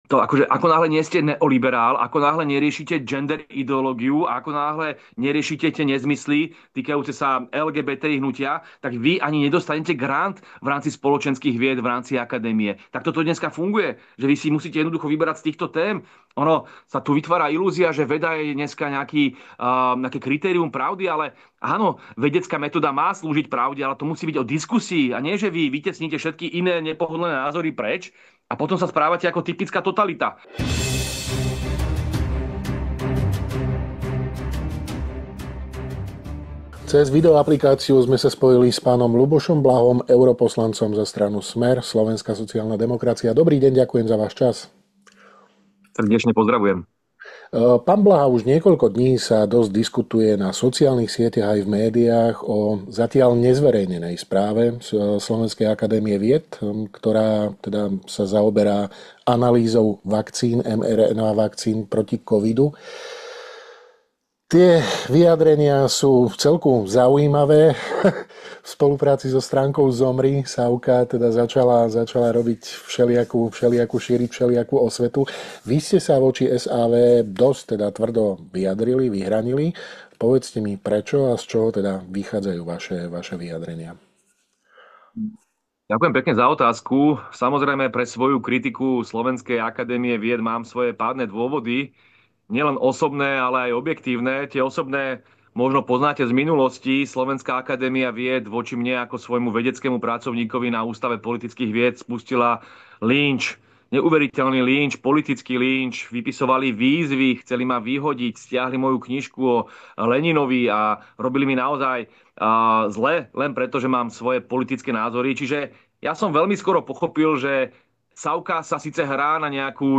Čo sa mu na celej veci nezdá a aké má voči tejto analýze vakcín výhrady? Dozviete sa vo videorozhovore pre Hlavné správy.